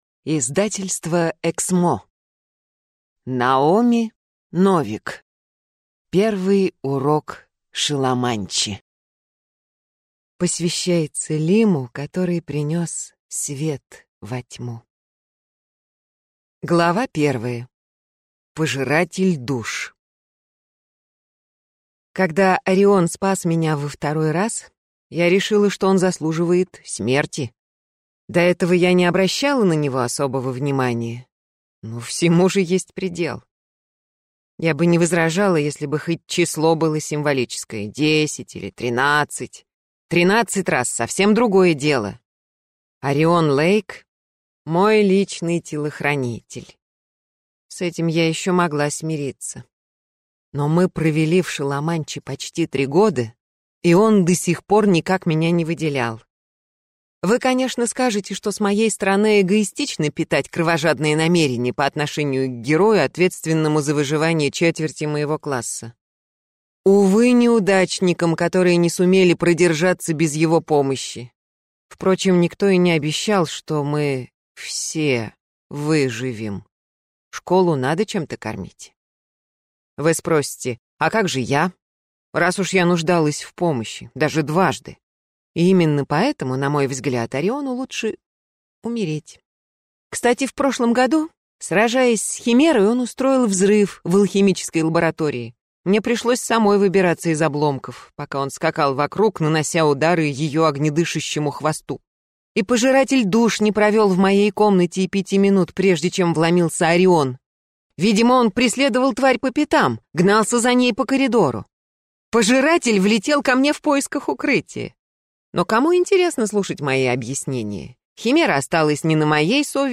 Аудиокнига Первый урок Шоломанчи | Библиотека аудиокниг